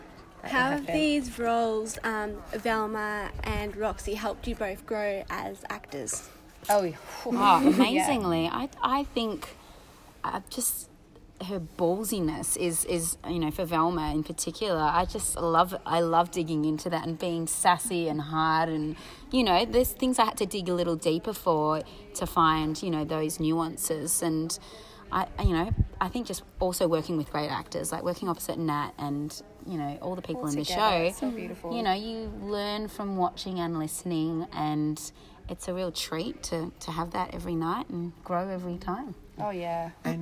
Chicago Media Call